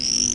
Frog Sound Effect
frog.mp3